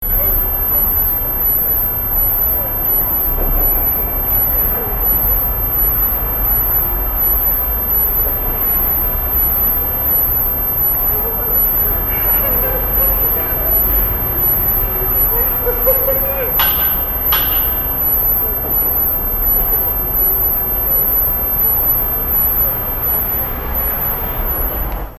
■大人には聞こえない着信音
ピッチダウン１
mosquito_sound2.mp3